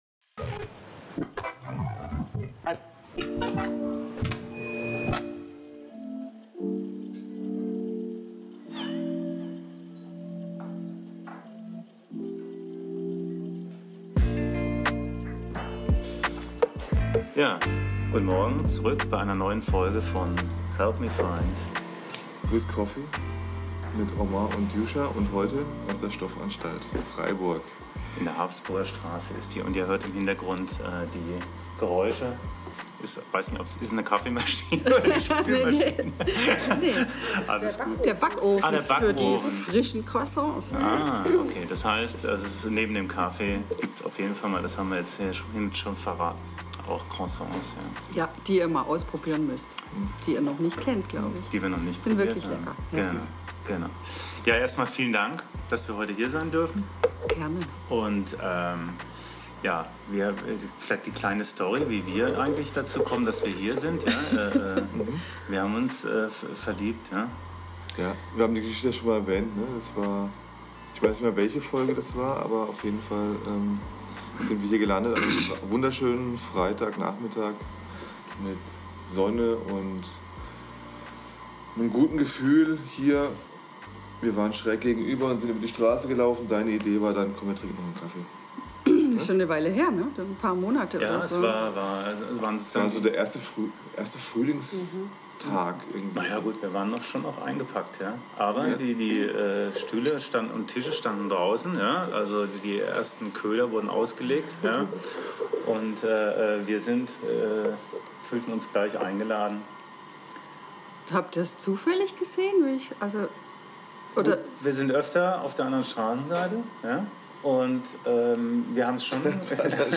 helpmefindgoodcoffee Episode 13 - Interview mit Stoffanstalt ~ helpmefindgoodcoffee Podcast